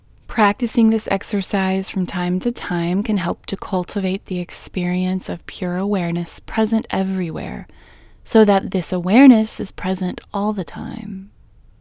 WHOLENESS English Female 20